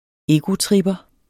Udtale [ ˈeːgotʁibʌ ]